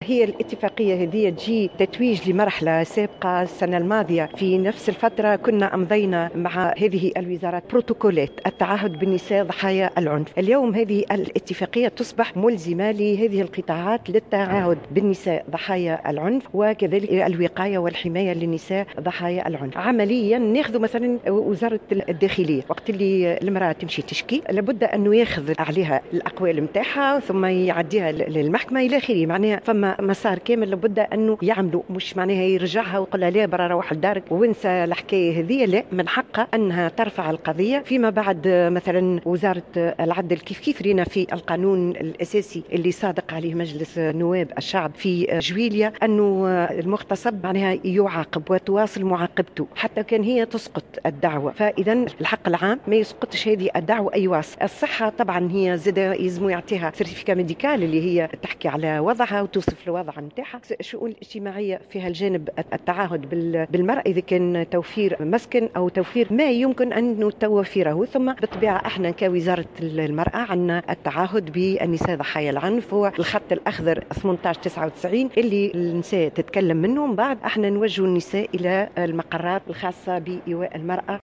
وقالت في تصريح لـ"الجوهرة اف أم" إن هذه الاتفاقية تتويج لبروتوكولات سابقة وأصبحت اليوم اتفاقية ملزمة لهذه الوزارات.